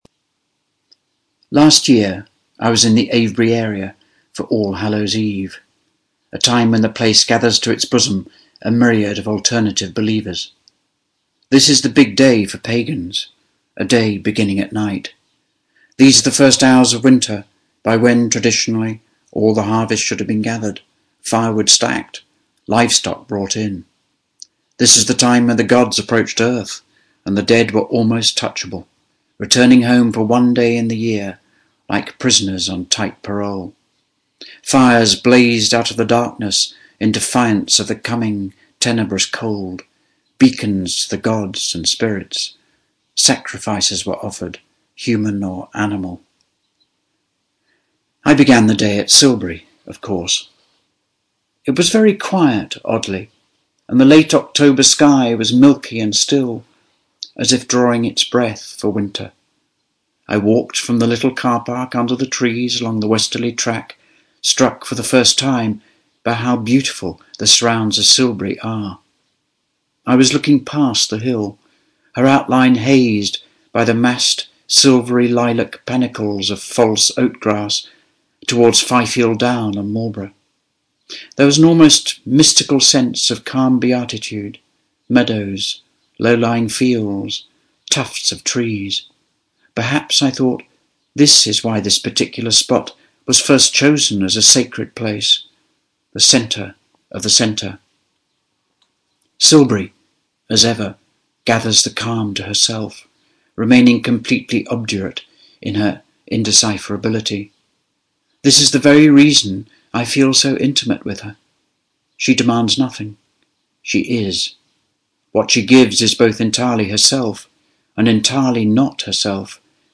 Adam Thorpe reading from On Silbury Hill